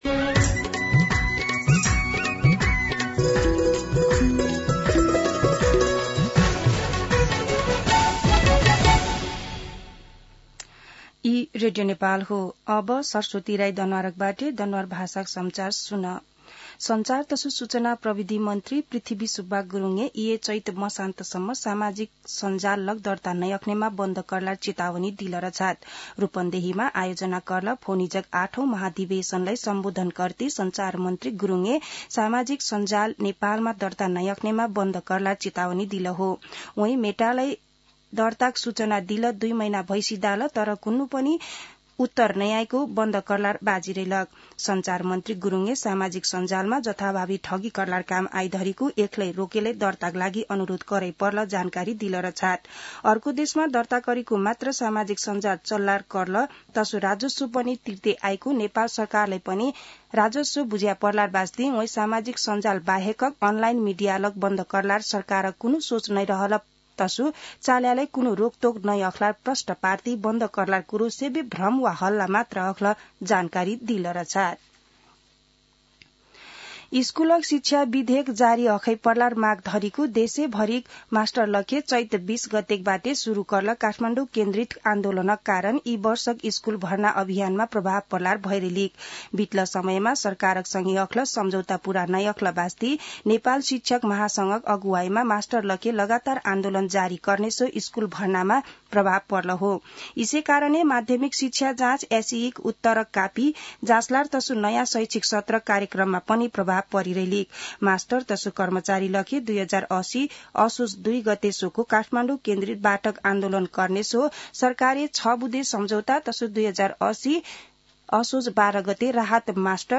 दनुवार भाषामा समाचार : ३० चैत , २०८१
Danuwar-News-6.mp3